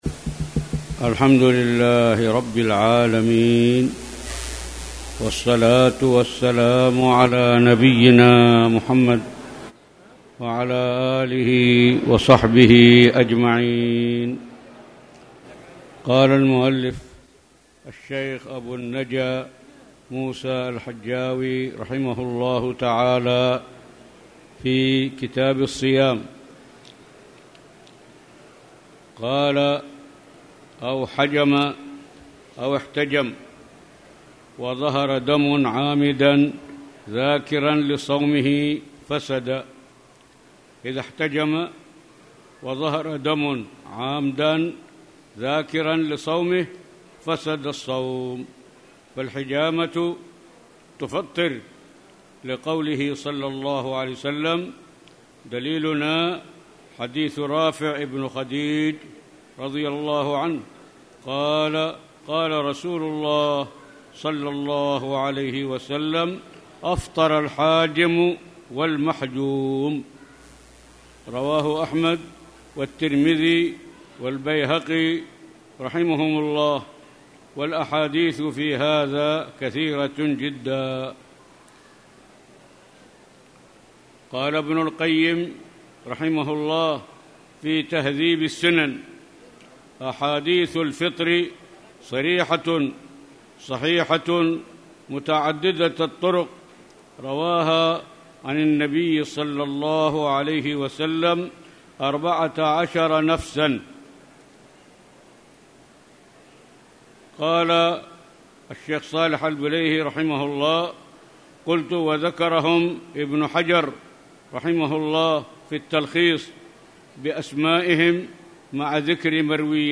تاريخ النشر ٢٤ شوال ١٤٣٨ هـ المكان: المسجد الحرام الشيخ